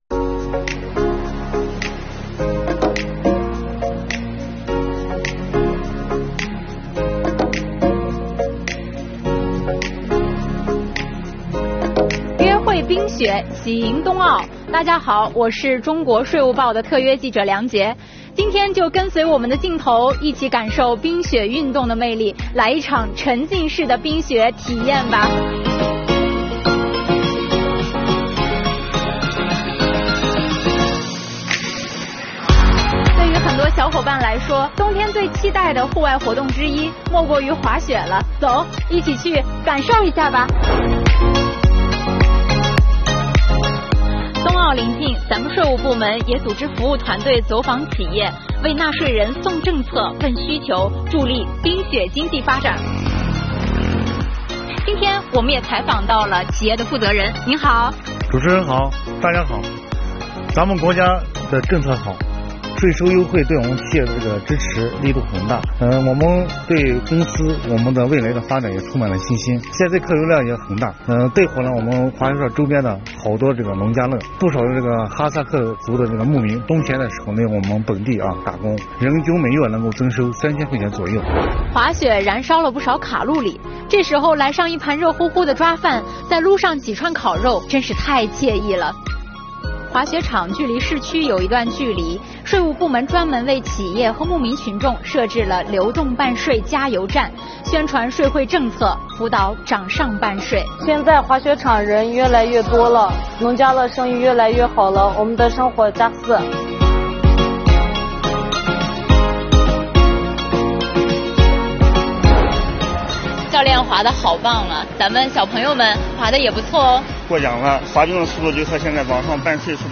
快来跟随镜头，跟本报记者一起走进新疆昌吉市的冰雪世界，滑雪、溜冰、赏冰灯，一起感受冰雪运动的魅力，来一场沉浸式的冰雪体验吧！